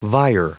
Prononciation du mot vier en anglais (fichier audio)
Prononciation du mot : vier